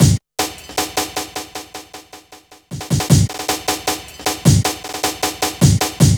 Hard Step 02-155.wav